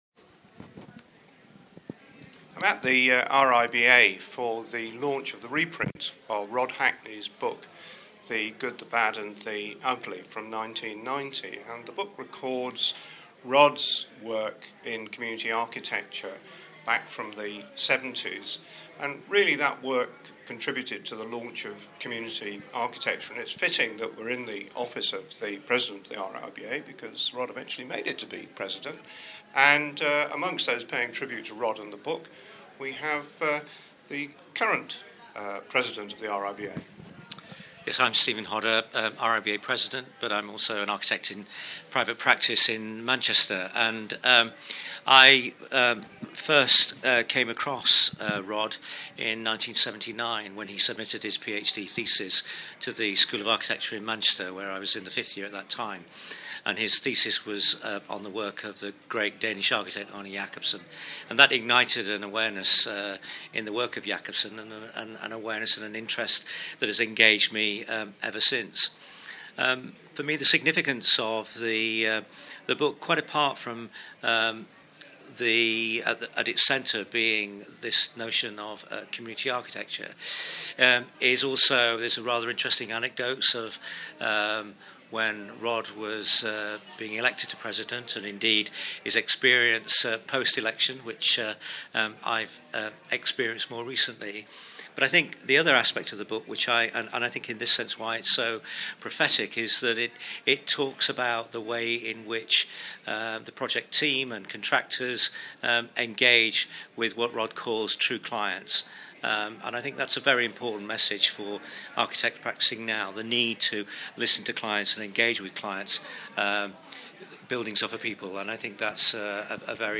Here Stephen reflects on the importance of community achitecture that Rod pioneered, and colleagues recall the early days of working with Rod.